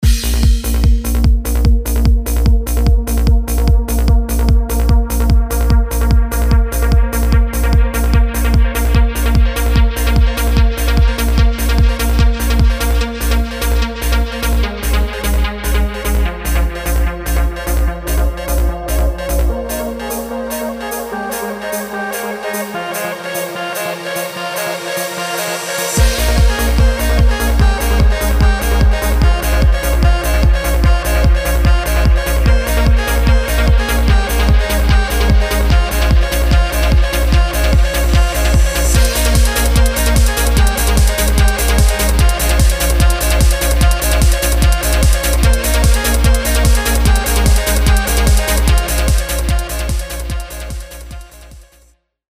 快速制作属于uplifting trance风格的迷幻激情之声
Trance 制作工具包
优质的 uplifting 式声音库，帮助你引导出属于 trance 的震撼能量
用混响、延时以及更多效果，为每个预设定制专属效果链
内含有150个风格正宗的 leads、铺底音、低音以及琶音素材